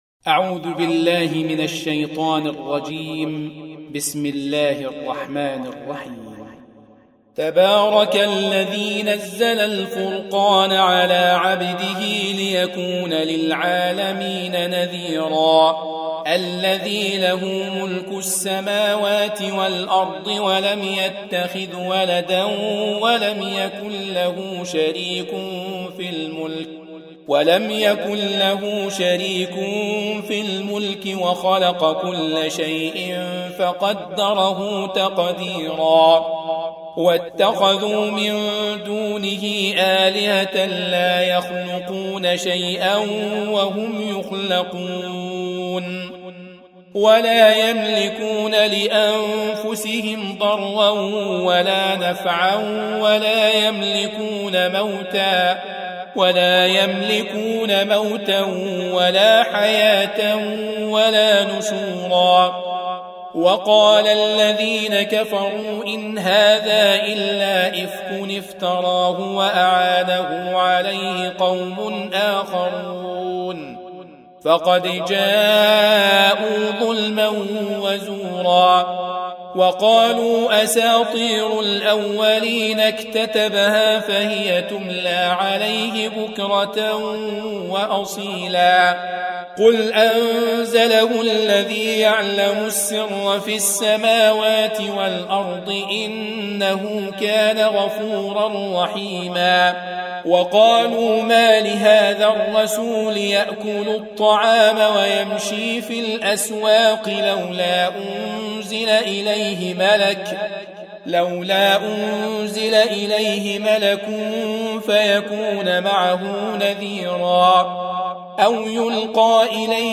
Surah Repeating تكرار السورة Download Surah حمّل السورة Reciting Murattalah Audio for 25. Surah Al-Furq�n سورة الفرقان N.B *Surah Includes Al-Basmalah Reciters Sequents تتابع التلاوات Reciters Repeats تكرار التلاوات